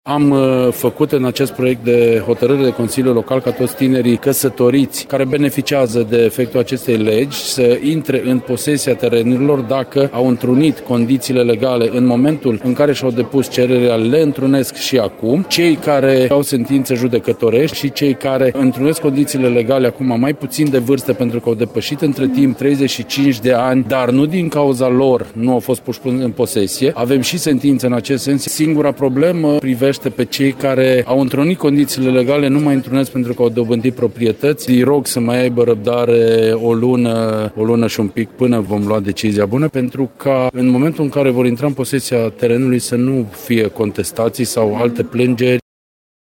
Prevederea se regăsește în proiectul de hotărâre care va fi supus votului consilierilor locali în următoarea ședință ordinară, spune viceprimarul Cosmin Tabără.